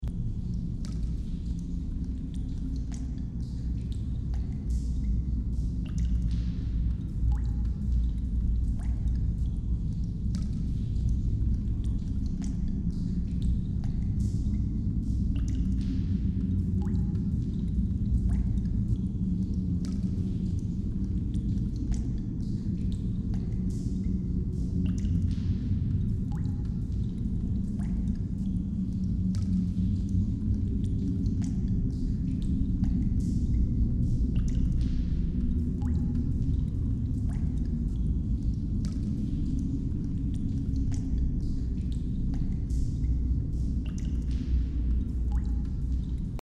دانلود آهنگ باد 52 از افکت صوتی طبیعت و محیط
جلوه های صوتی
دانلود صدای باد 52 از ساعد نیوز با لینک مستقیم و کیفیت بالا